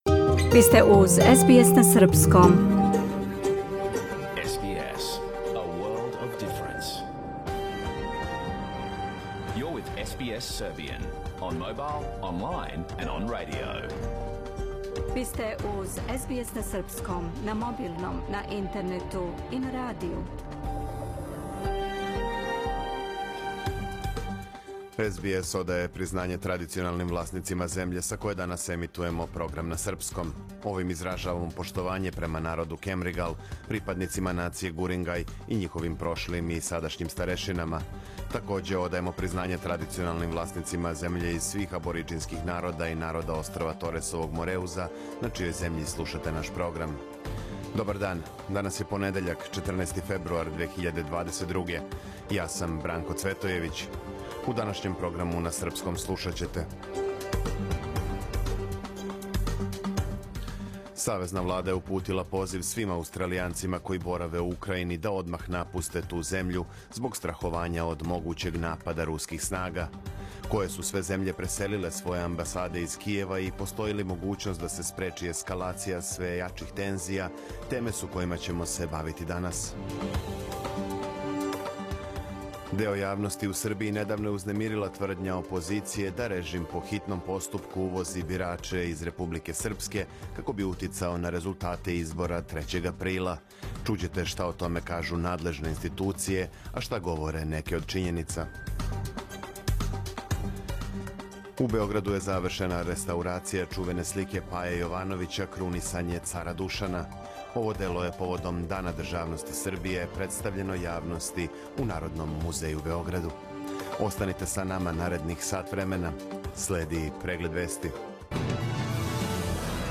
Програм емитован уживо 14. фебруара 2022. године
Ако сте пропустили нашу емисију, сада можете да је слушате у целини без реклама, као подкаст.